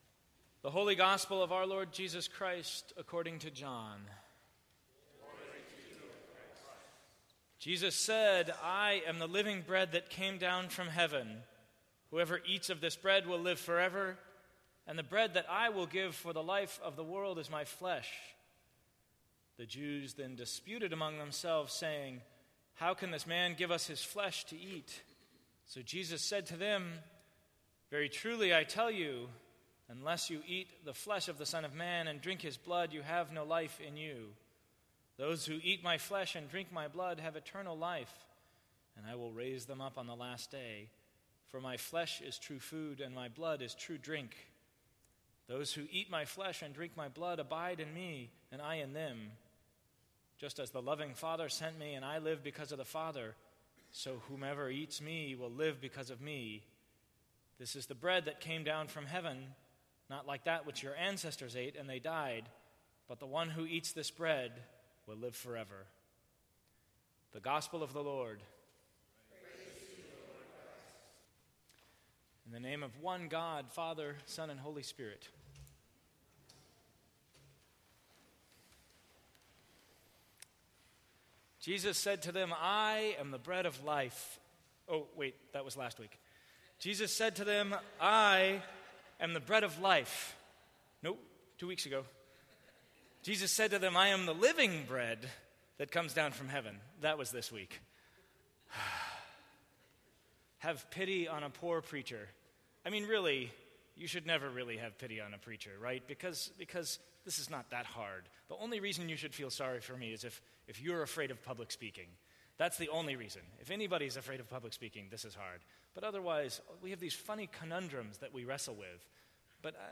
Sermons from St. Cross Episcopal Church Flesh and Blood Sep 24 2015 | 00:10:12 1x 00:00 / 00:10:12 Subscribe Share Apple Podcasts Spotify Overcast RSS Feed Share Link Embed